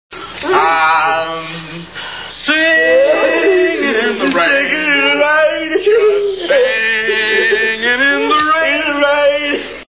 A Clockwork Orange Movie Sound Bites